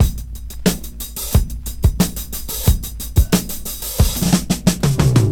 • 90 Bpm Vinyl Record (17 Drum Loop Sample D# Key.wav
Free breakbeat sample - kick tuned to the D# note. Loudest frequency: 2117Hz
90-bpm-vinyl-record-(17-drum-loop-sample-d-sharp-key-jqg.wav